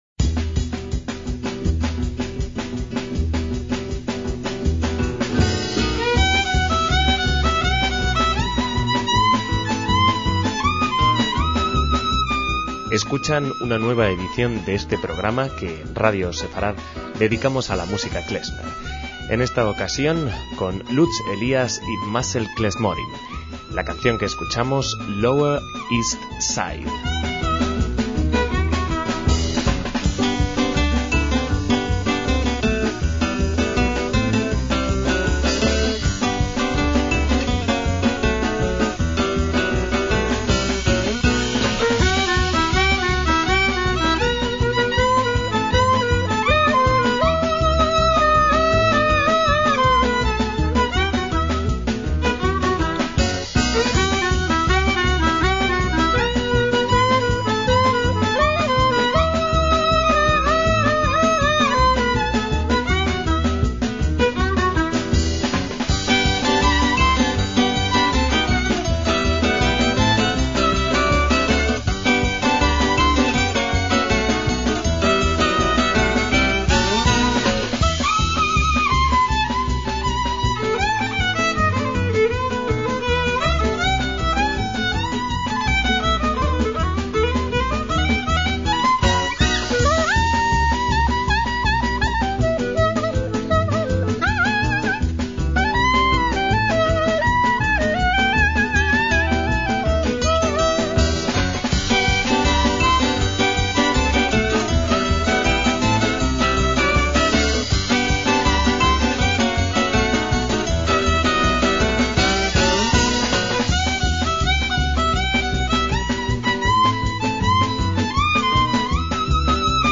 MÚSICA KLEZMER
klezmer alemán con aires de jazz francés